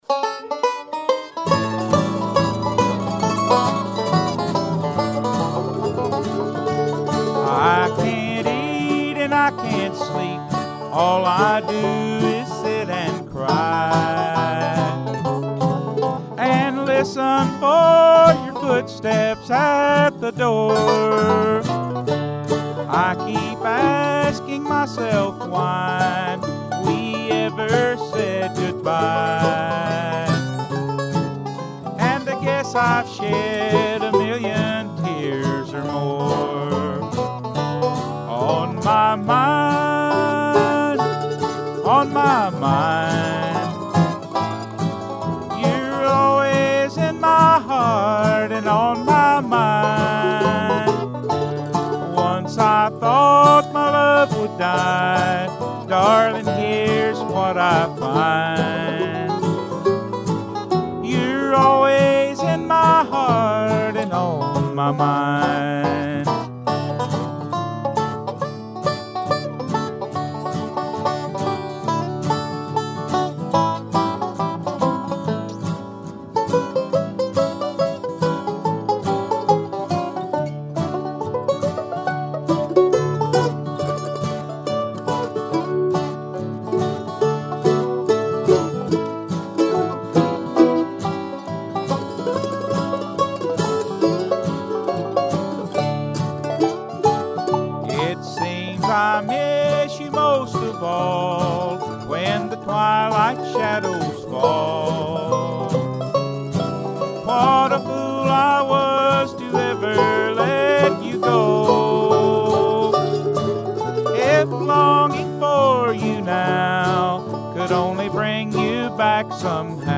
A little sloppy in many ways, but still I'm okay with it, I think.